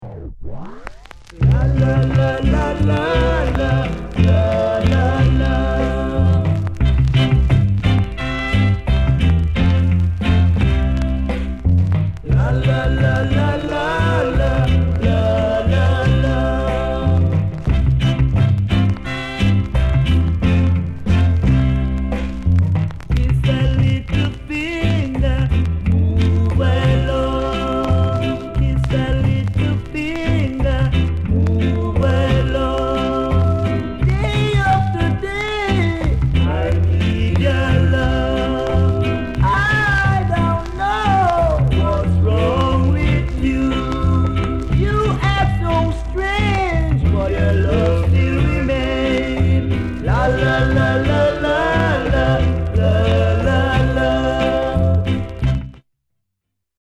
SOUND CONDITION A SIDE VG(OK)
SKINHEAD